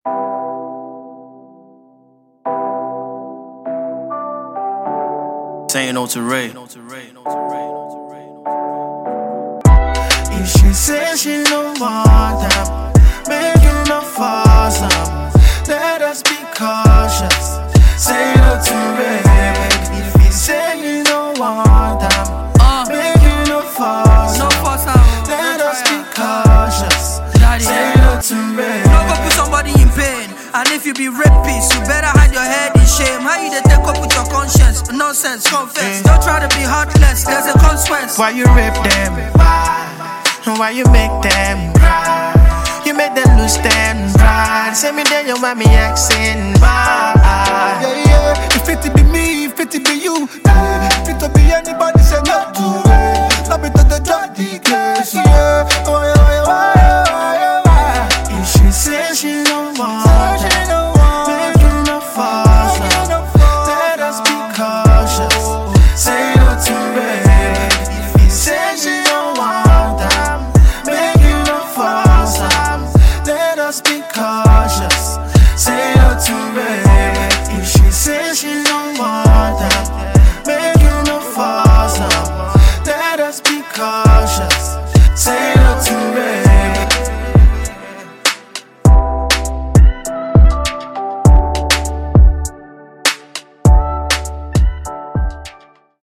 Talented rapper
banging song